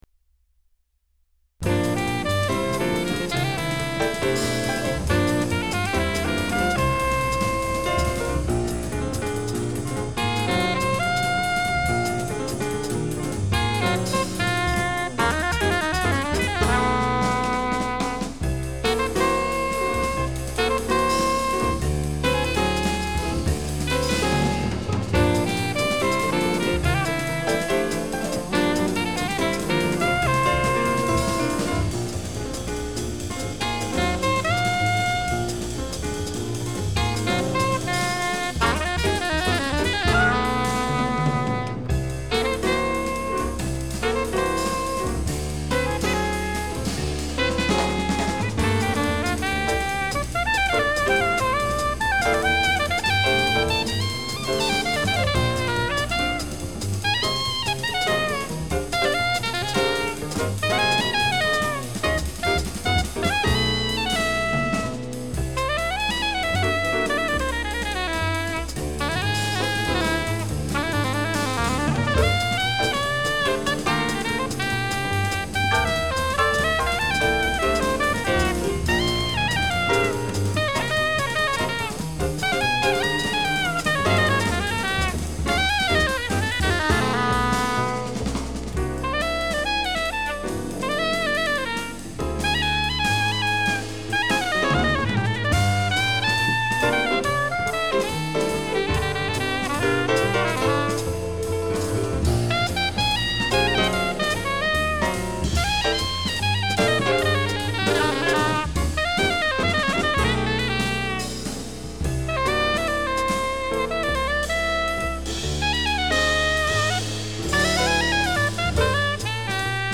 Geneva, Aquarius Studio - 1977
sax soprano